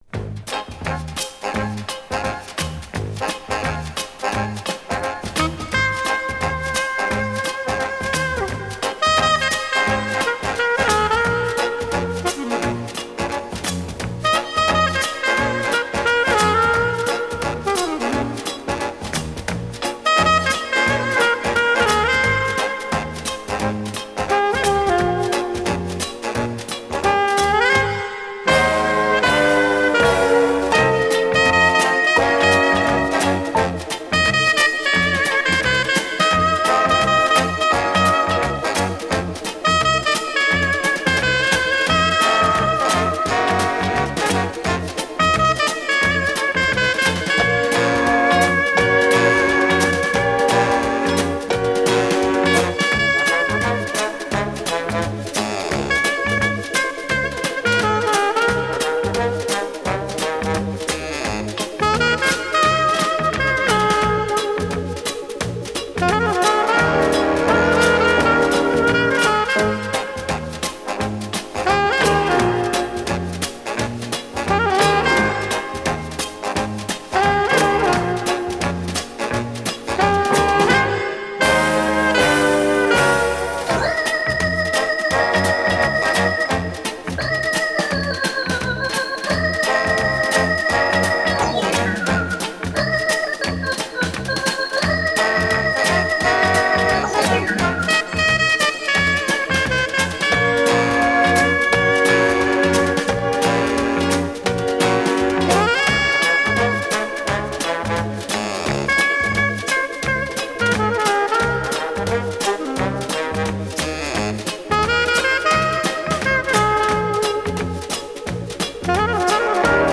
意大利萨克斯管
Жанр: easy listening